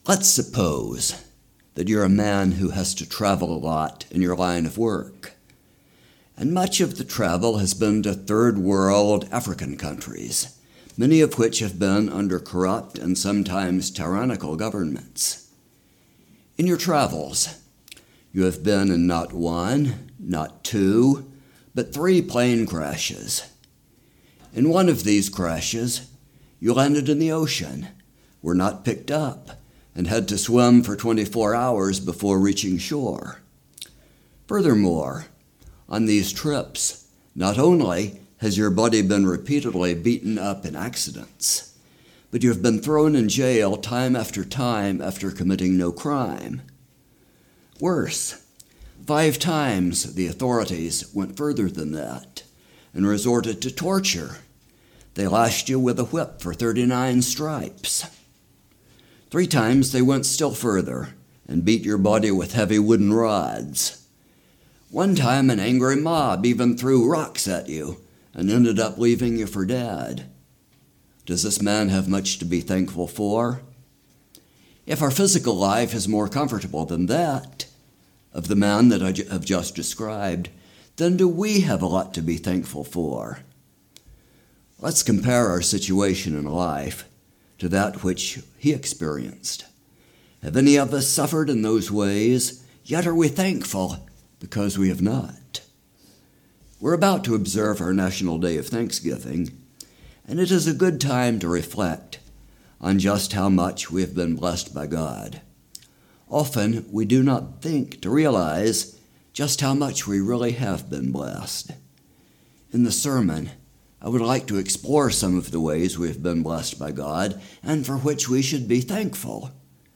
In this sermon we explore some of the ways we have been blessed by God (and for which we should be thankful), reasons that we tend to forget these blessings, and what we should do to acquire and maintain a thankful attitude.